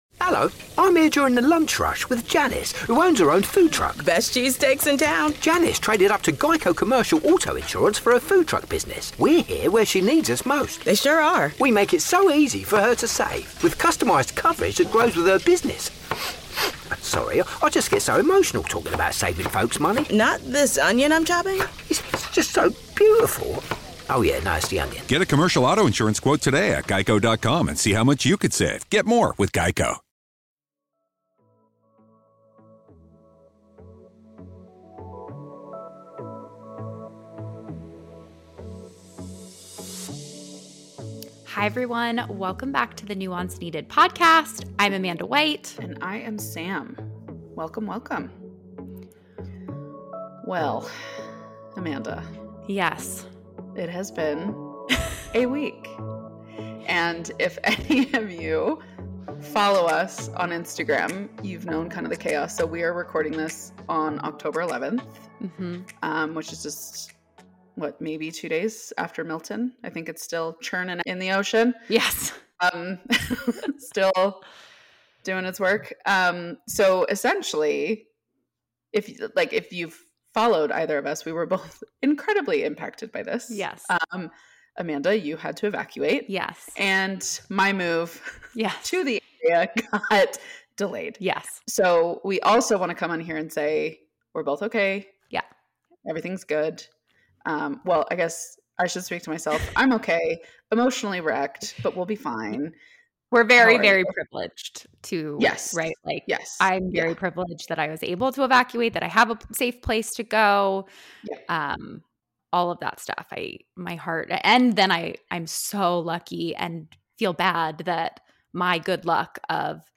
* Please note we recorded this during the evacuation, so the audio is not up to our normal level.